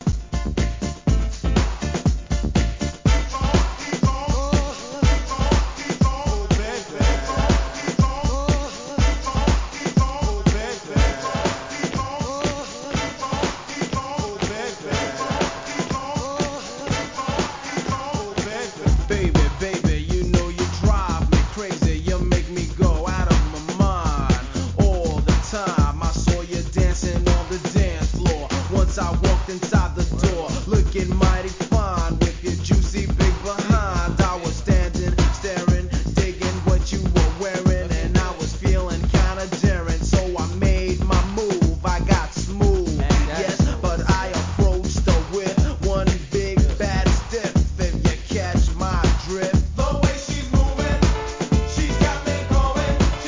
HIP HOUSE